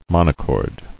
[mon·o·chord]